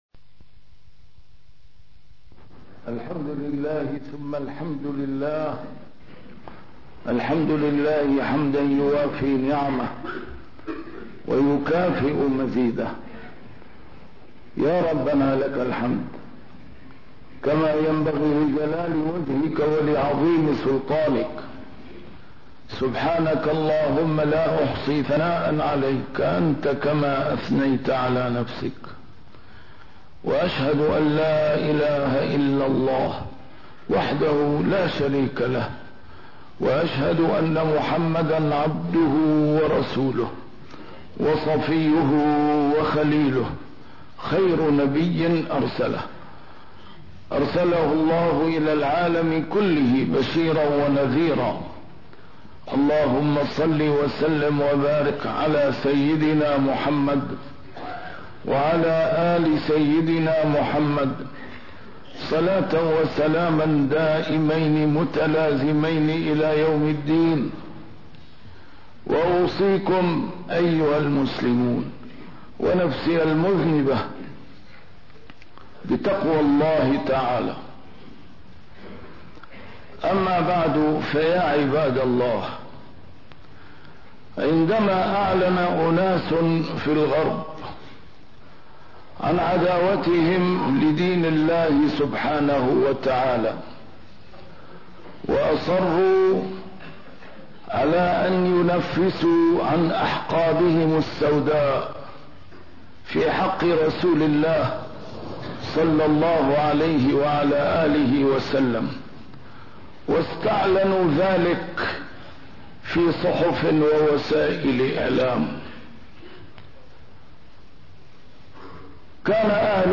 A MARTYR SCHOLAR: IMAM MUHAMMAD SAEED RAMADAN AL-BOUTI - الخطب - الحب أرقى شعور إنساني فلا تمسخوه على الطريقة الغربية